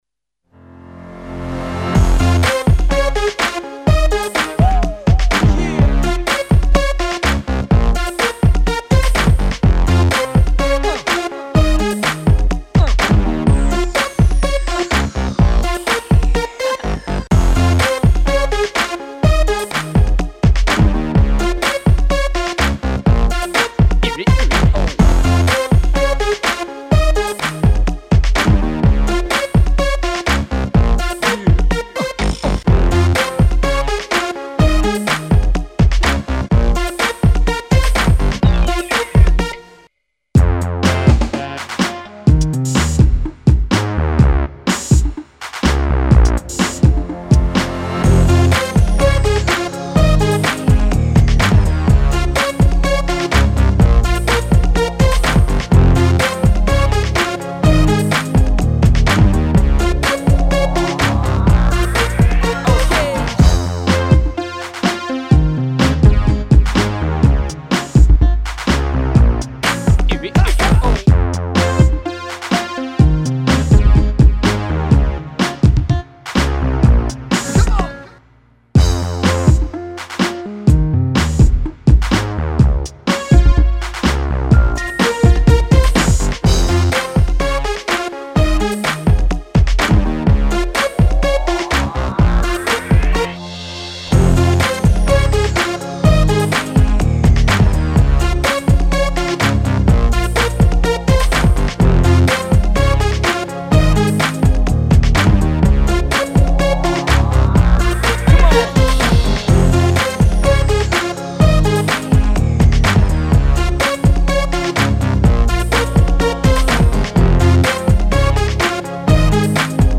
Klubnaya_muzyka_Veselaya_zazhigatelnaya_muzyka_dlya_tanca_gou_gou_mp3co_co.mp3